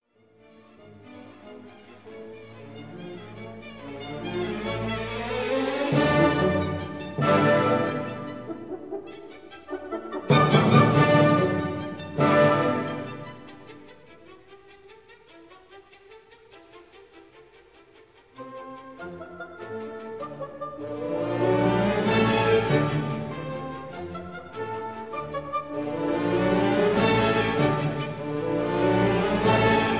1960 stereo recording